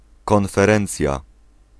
Ääntäminen
Synonyymit entretien Ääntäminen France: IPA: [kɔ̃.fe.ʁɑ̃s] Haettu sana löytyi näillä lähdekielillä: ranska Käännös Ääninäyte Substantiivit 1. prelekcja 2. konferencja {f} Muut/tuntemattomat 3. wykład {m} Suku: f .